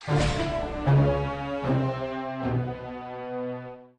level_failed.ogg